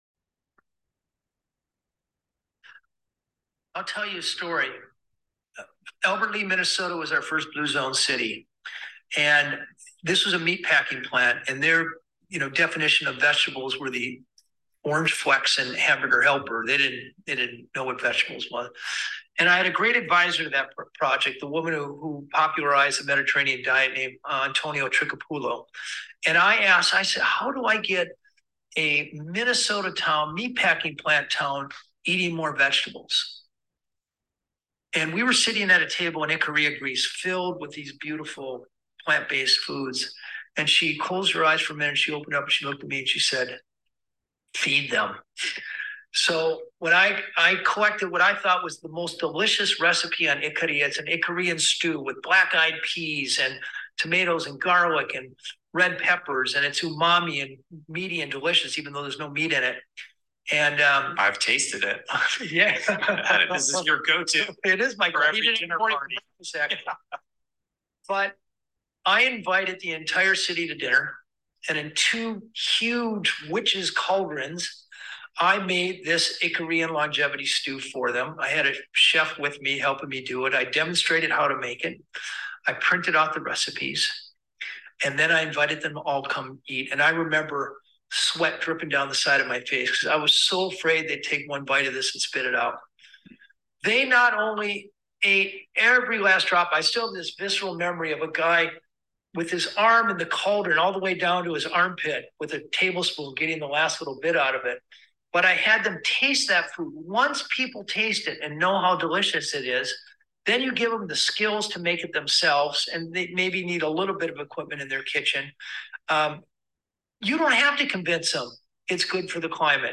4 minute clip of Dan Buettner discussing why he loves this recipe on a recent Rich Roll Podcast.